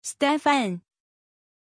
Aussprache von Stefan
pronunciation-stefan-zh.mp3